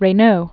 (rā-nō), Paul 1878-1966.